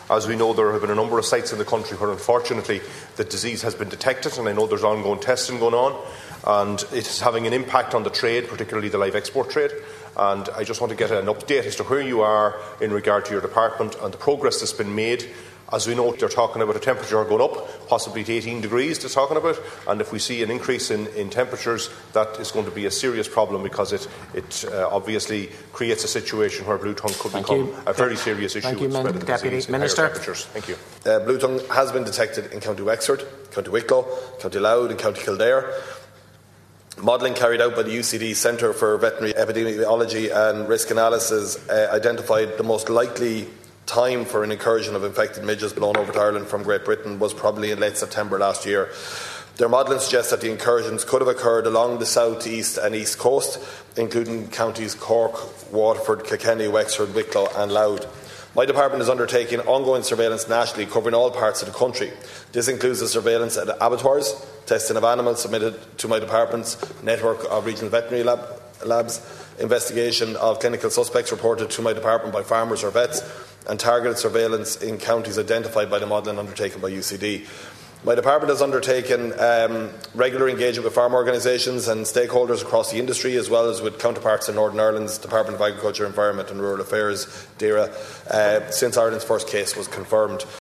In the Dail this morning, Agriculture Minister Martin Heydon said the disease has been detected in four counties ; Wexford, Wicklow, Kildare and Louth.
He was answering questions from Sligo, Leitrim and South Donegal TD Martin Kenny…………